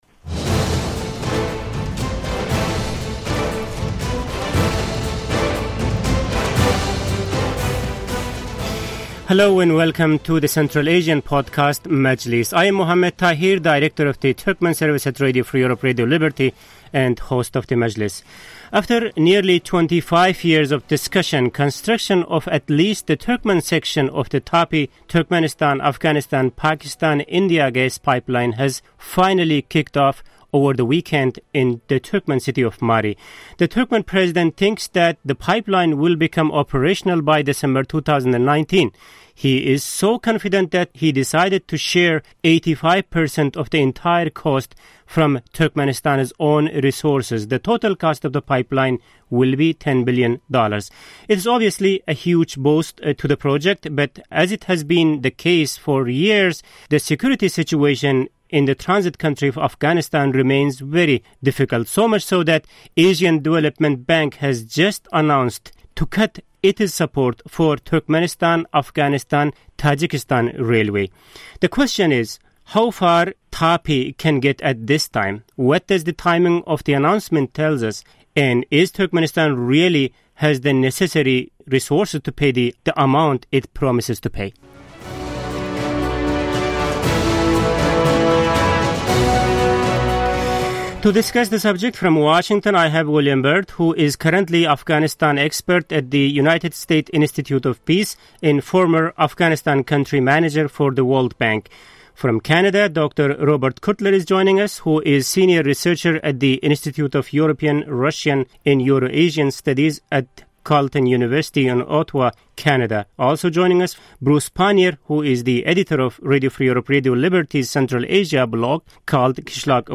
Roundtable: Turkmenistan's TAPI Dream